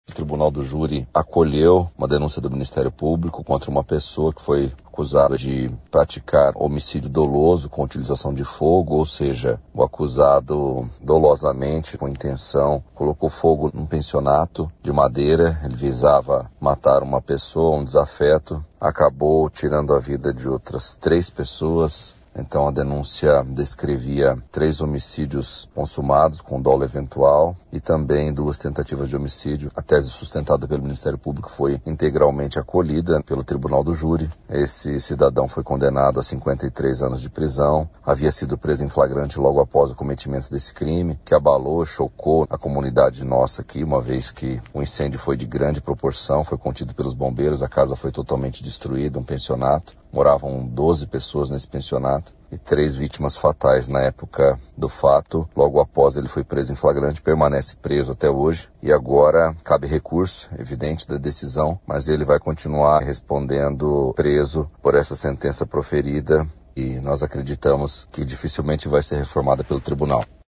Ouça o que diz o promotor de Justiça, Julio Cesar Silva: